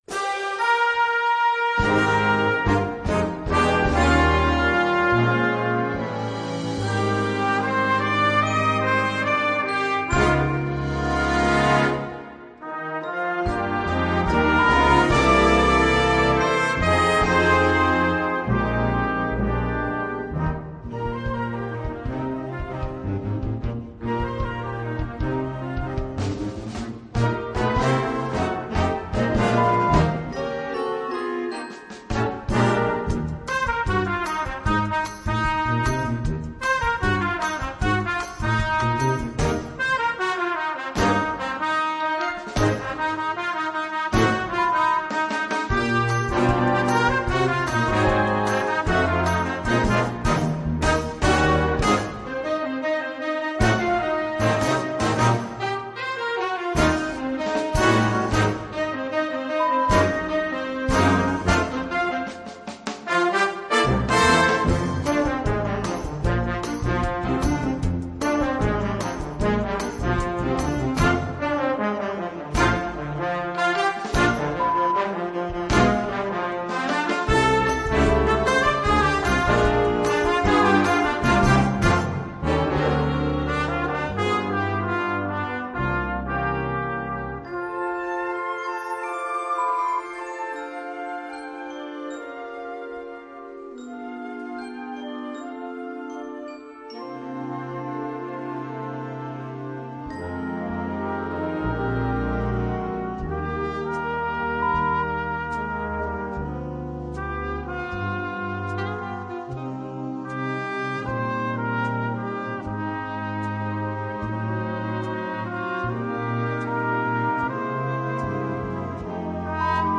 Besetzung: Blasorchester
Dieses Medley für Blasorchester enthält